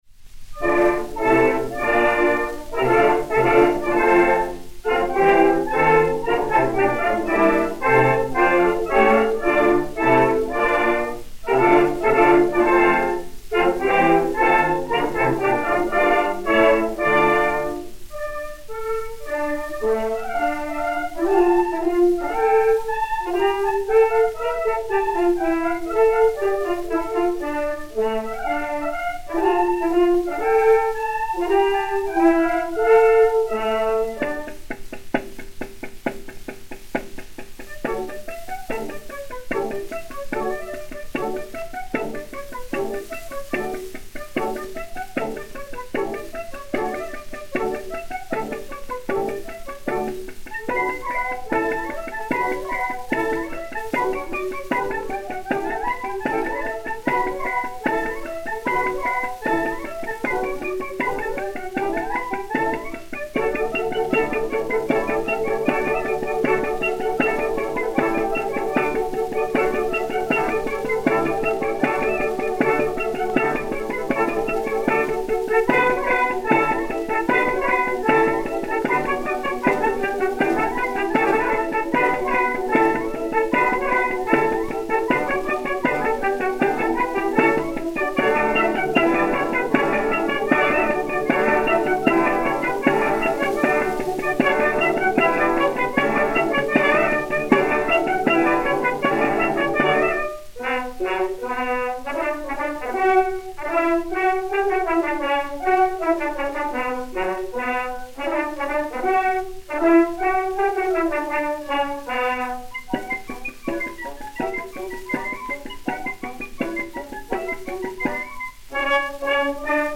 Yvette Guilbert (la Renaude), X (Balthazar) et Orchestre